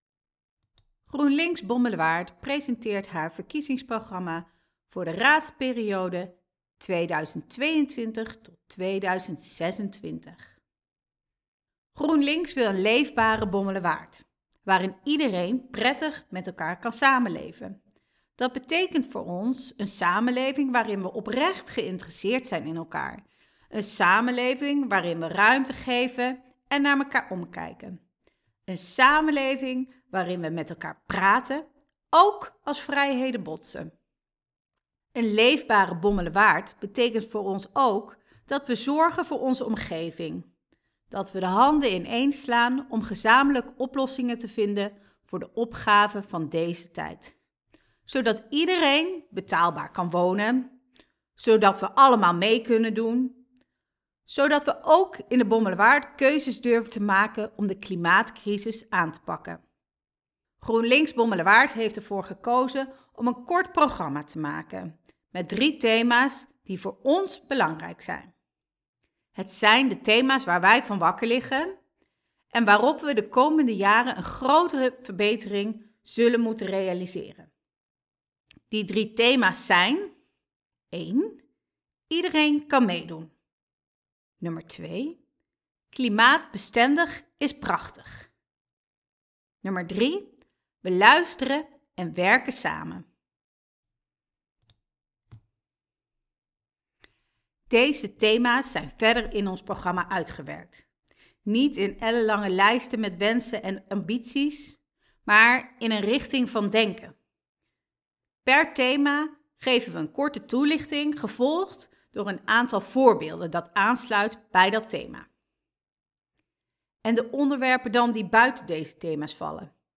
voorgelezen verkiezingsprogramma 2.mp3